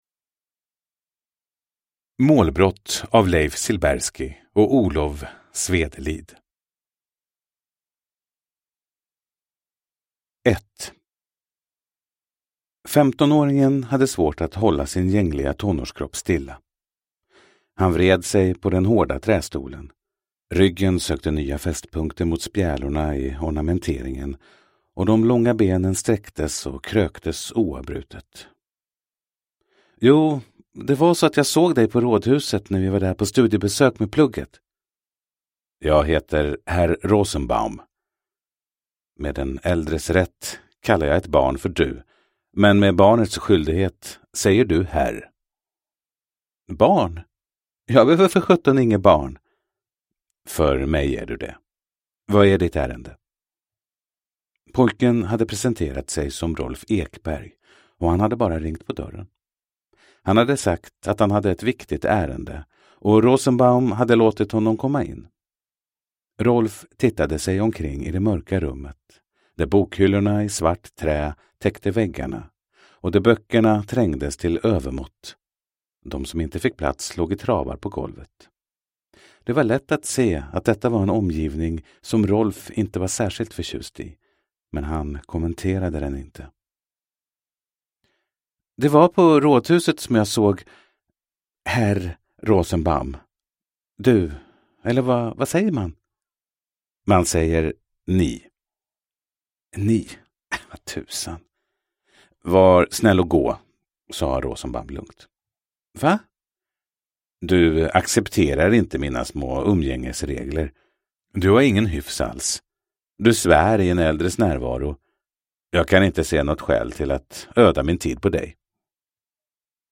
Målbrott – Ljudbok